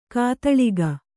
♪ kātaḷiga